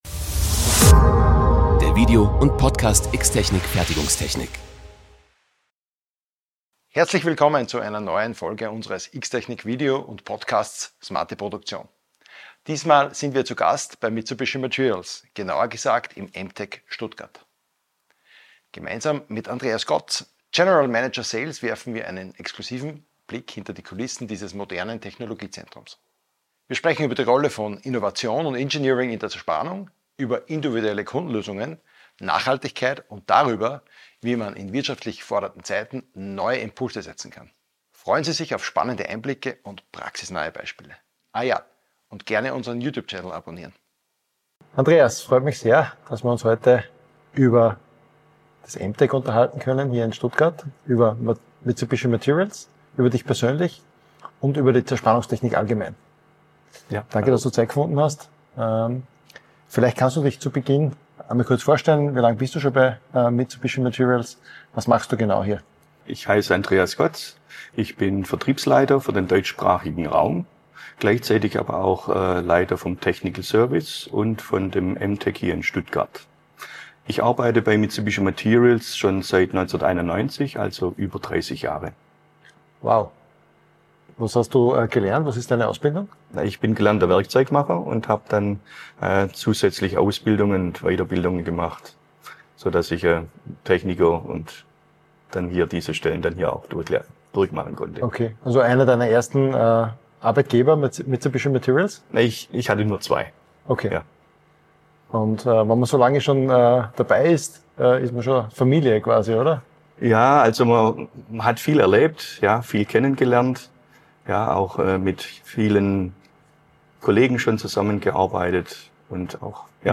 Wir erfahren, wie Kunden durch praxisnahe Unterstützung, digitale Simulationen und maßgeschneiderte Werkzeuglösungen profitieren – und warum gerade jetzt der richtige Zeitpunkt ist, Prozesse neu zu denken. Ein inspirierender Austausch über aktuelle Herausforderungen in der Industrie, Fachkräftemangel, neue Werkstoffe und die Zukunft der Produktion.